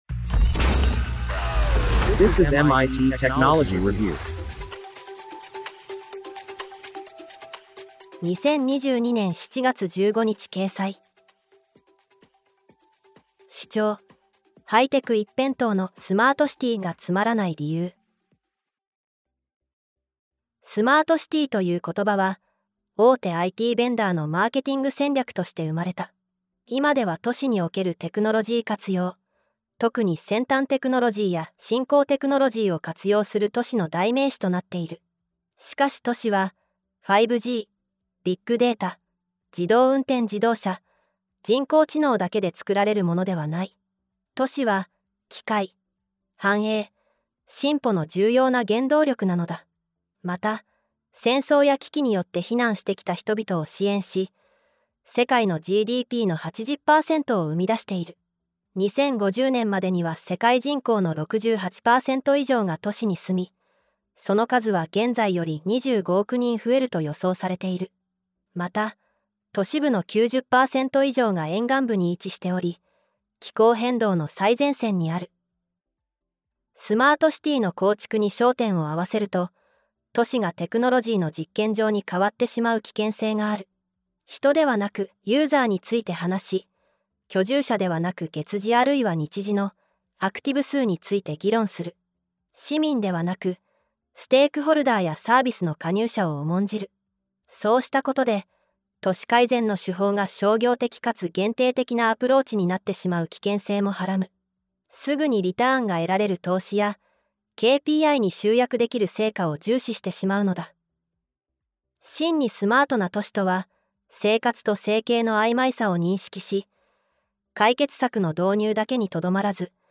—— 都市に今求められているもの ◆ MITテクノロジーレビューは毎週、旬のテーマを設定し、編集部がピックアップした記事を「オーディオ・ムック（β）」として音声化してお届けします。
なお、本コンテンツは音声合成技術で作成しているため、一部お聞き苦しい点があります。